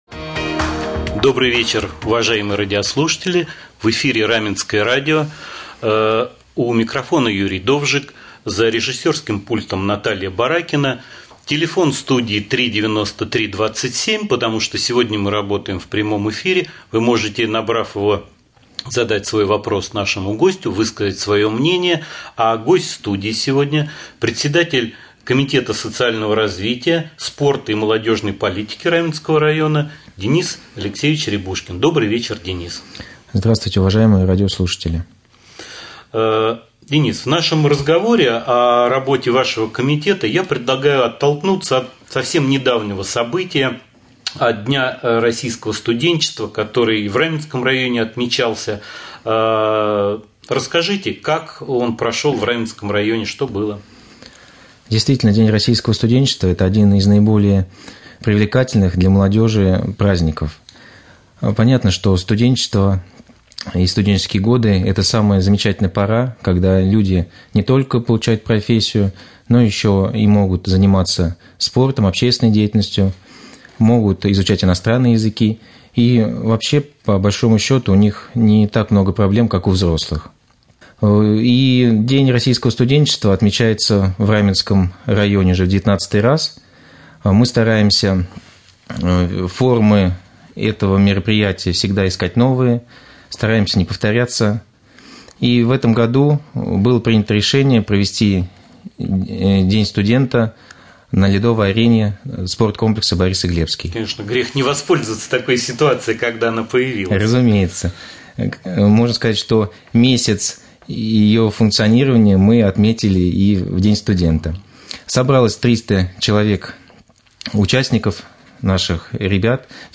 Прямой эфир с председателем комитета социального развития